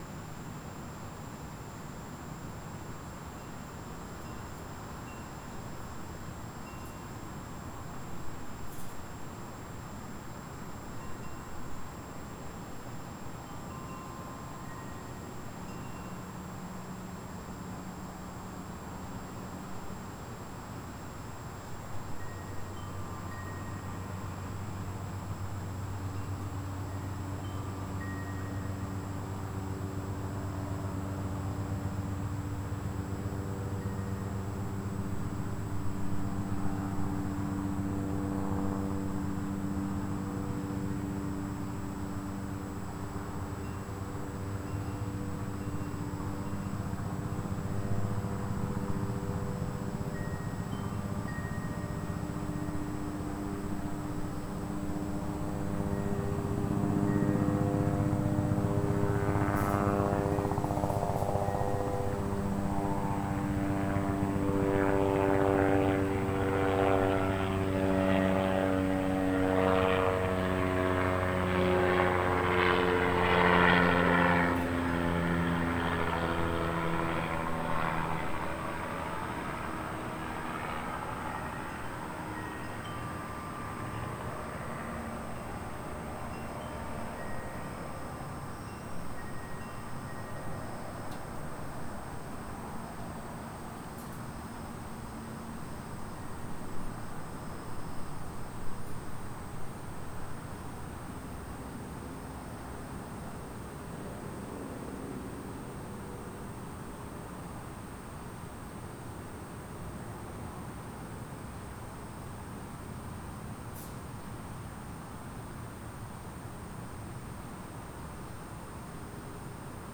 Sample #7: Helicopter (02:11) (11.5MB/file).
B-format files for experimental mic.
Helicopter passing over my front yard on April 29, 2008.